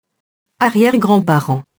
arrière-grands-parents [arjɛrgrɑ̃parɑ̃] nom masculin pluriel